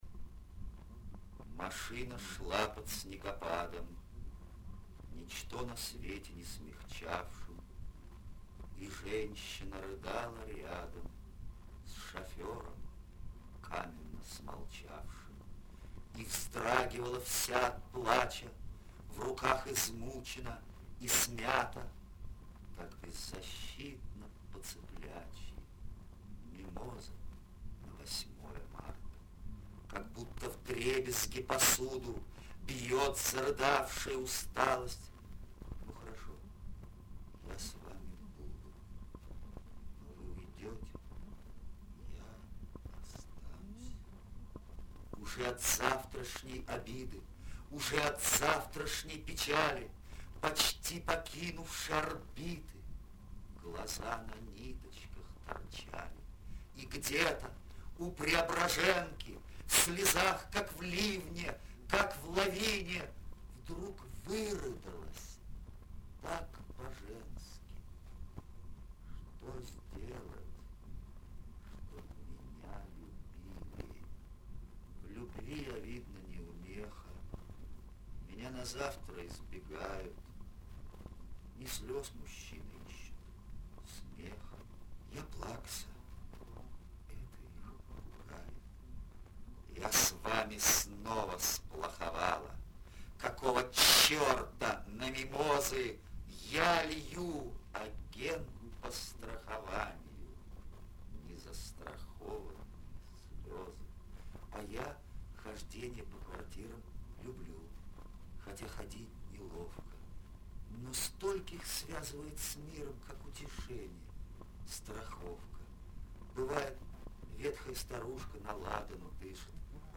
На ру трекере есть раздача - Читает Евгений Евтушенко, именно в ней я нашел это стихотворение. Оно было на диске, выпущенном в 1980 году - Голубь в Сантьяго и другие стихи